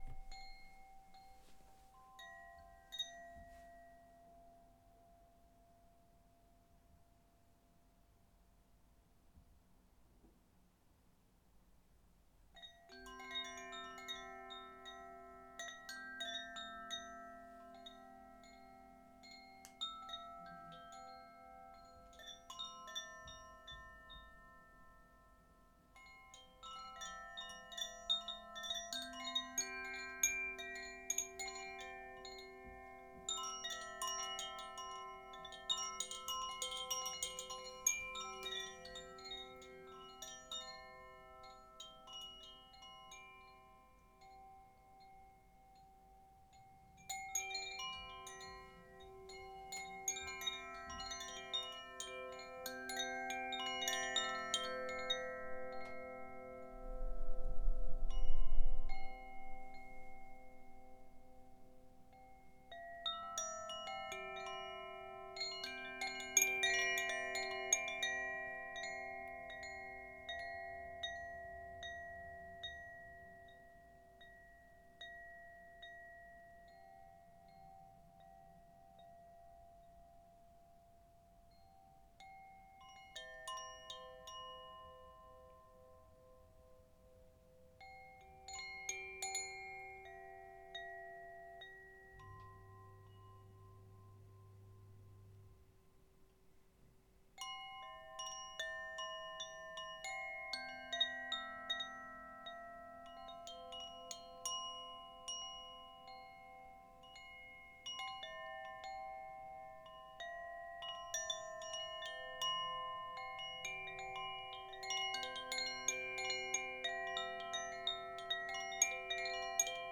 Wind Chime relaxing stereo studio sound quality 192Khz
bell chime ding healing metal metallic percussion relaxing sound effect free sound royalty free Nature